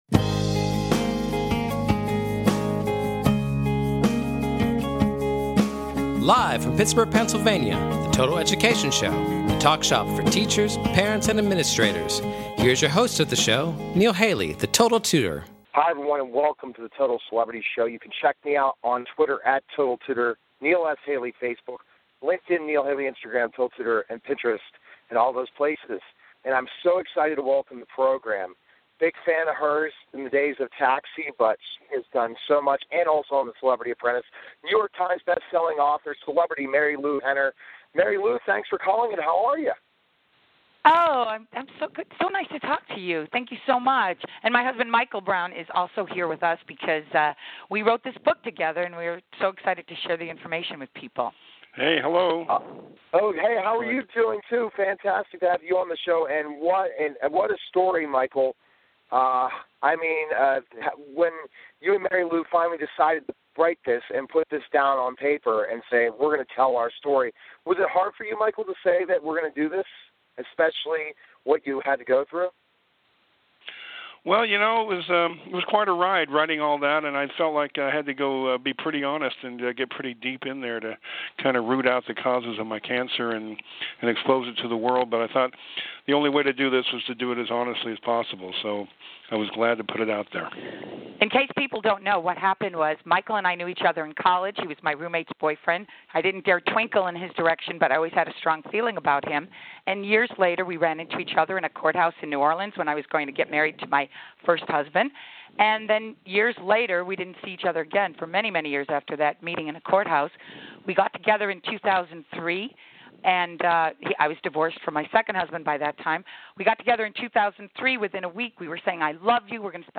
Catch weekly discussions focusing on current education news at a local and national scale.